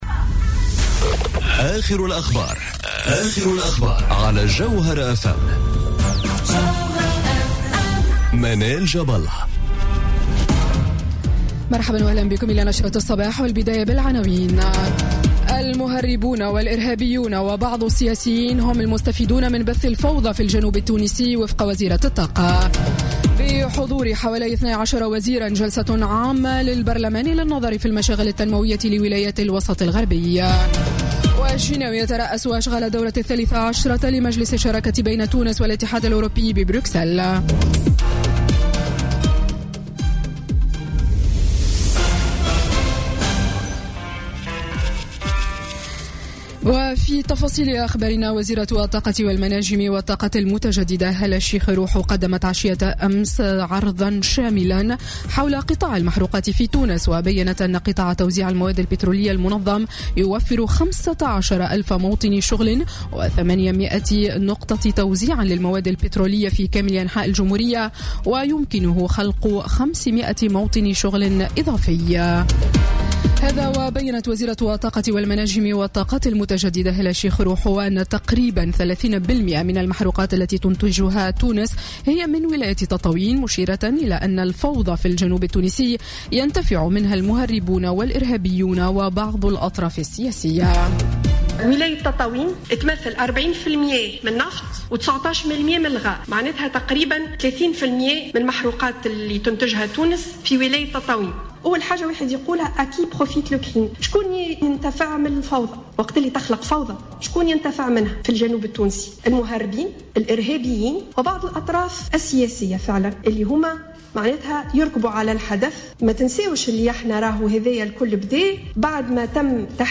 نشرة أخبار السابعة صباحا ليوم الثلاثاء 9 ماي 2017